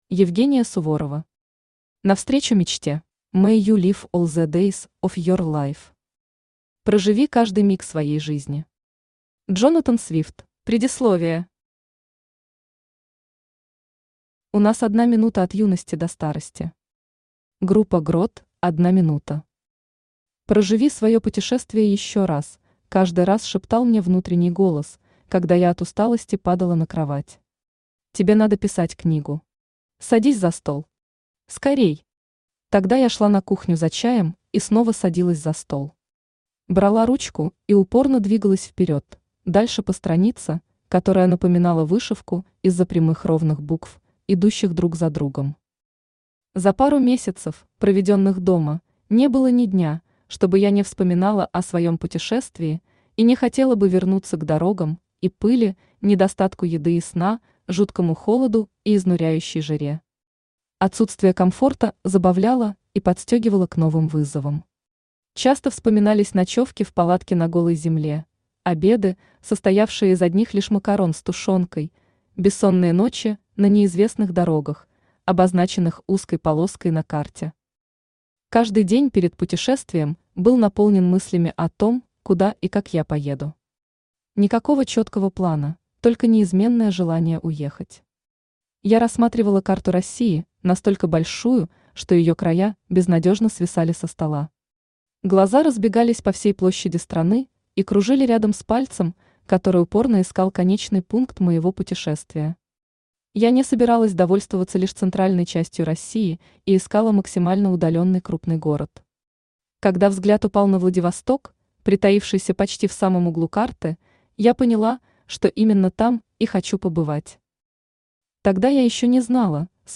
Аудиокнига Навстречу мечте | Библиотека аудиокниг
Aудиокнига Навстречу мечте Автор Евгения Владимировна Суворова Читает аудиокнигу Авточтец ЛитРес.